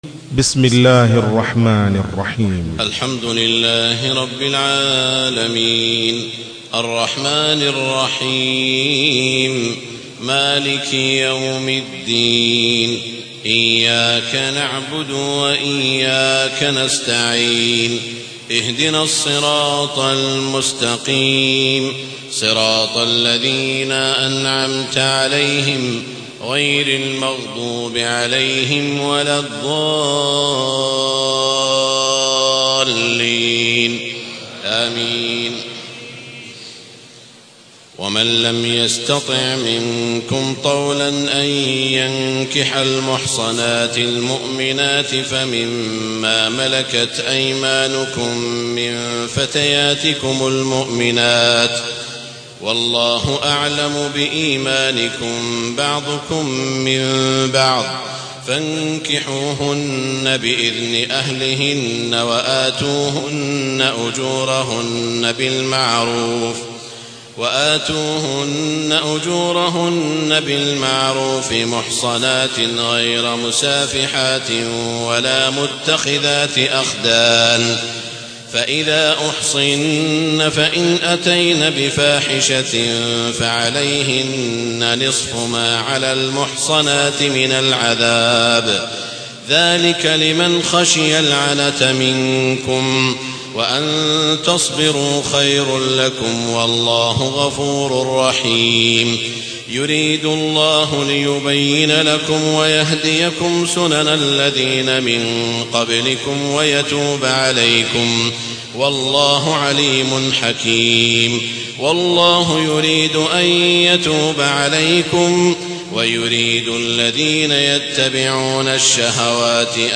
تهجد ليلة 25 رمضان 1432هـ من سورة النساء (25-99) Tahajjud 25 st night Ramadan 1432H from Surah An-Nisaa > تراويح الحرم المكي عام 1432 🕋 > التراويح - تلاوات الحرمين